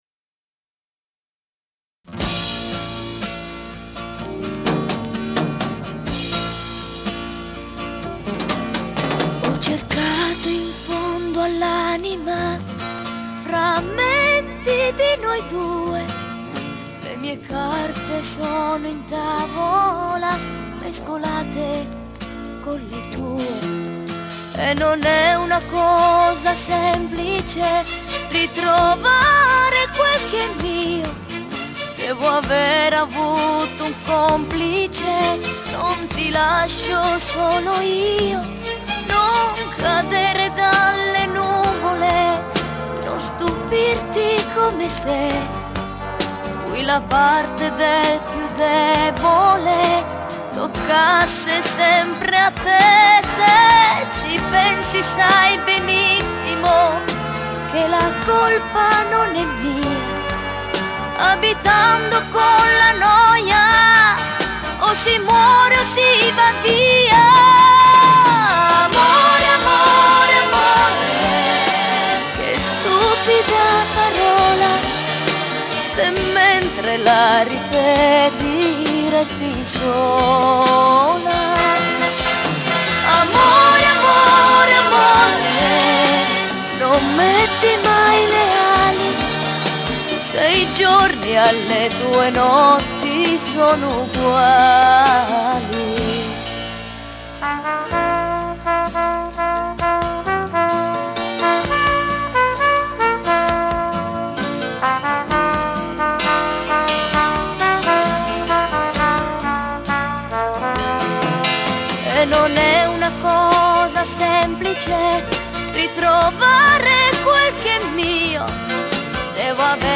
Basso
Batteria
Chitarra Acustica
Tastiere